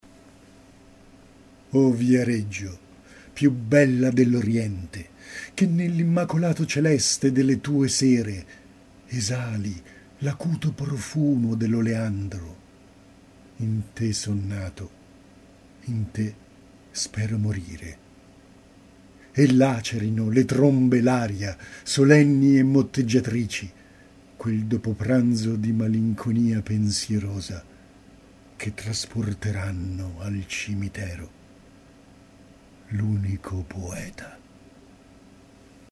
Recitazione